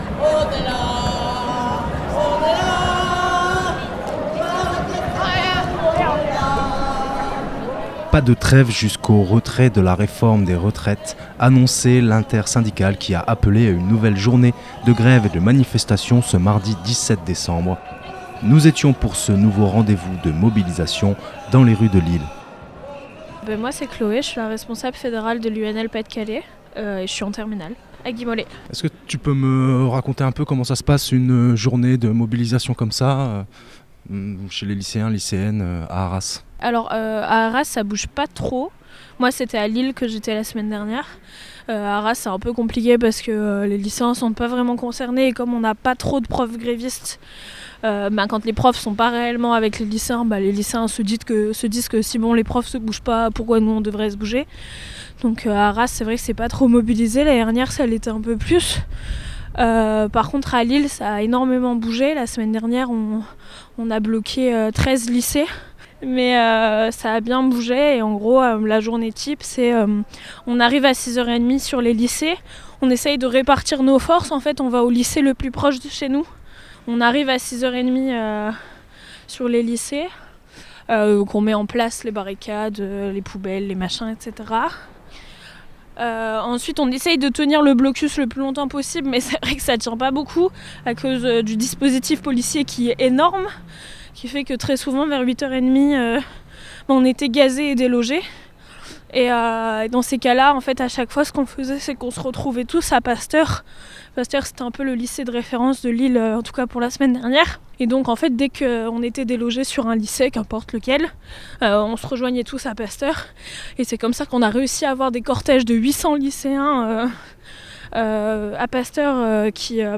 Nouvelle journée de grève et de manifestations ce mardi 17 décembre. Micros-rebelles était à Lille.